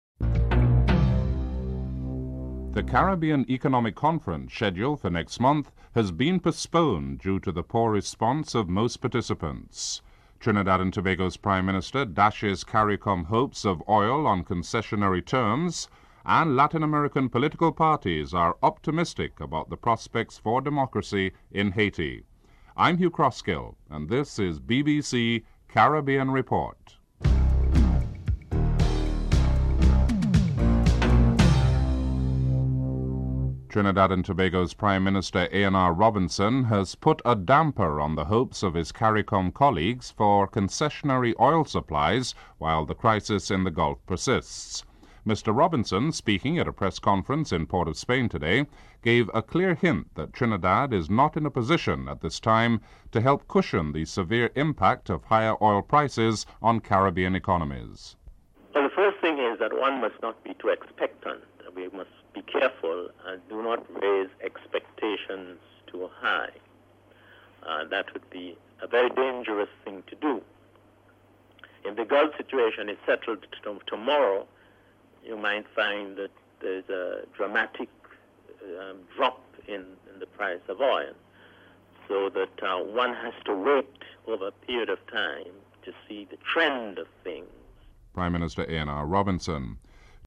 The British Broadcasting Corporation
1. Headlines (00:00-00:33)